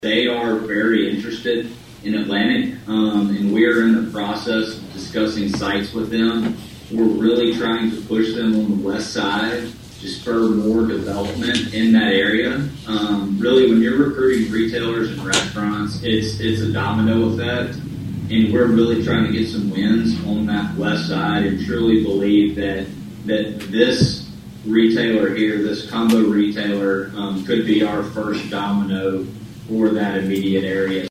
attended the Atlantic City Council meeting via Zoom on Wednesday.